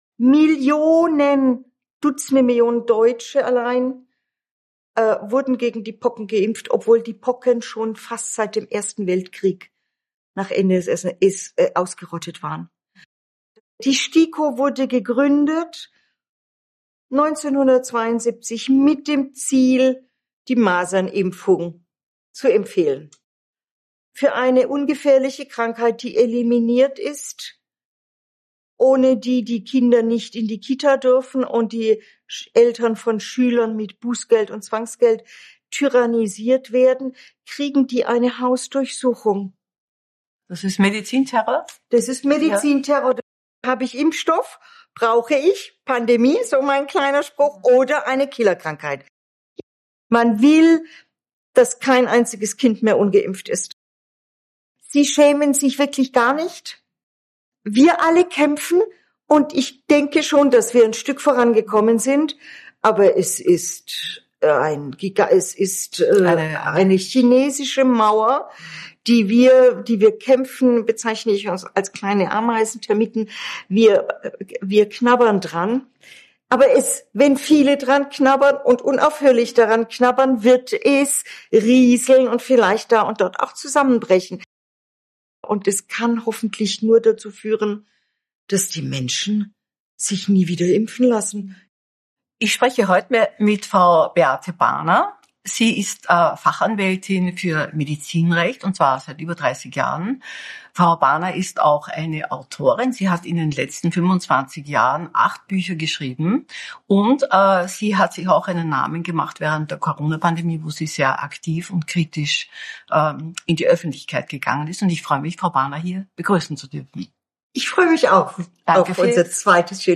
erhebt im Gespräch mit AUF1 schwere Vorwürfe gegen Politik